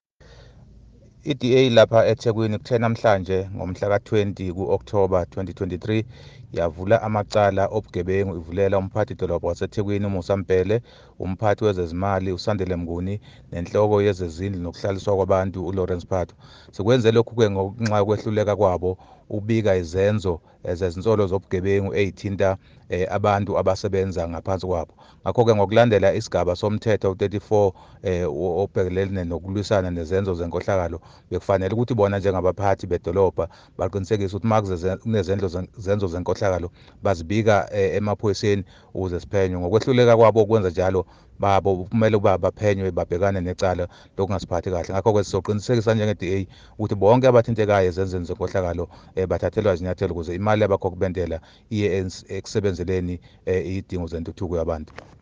IsiZulu soundbites by Councillor Thabani Mthethwa – DA eThekwini Caucus Leader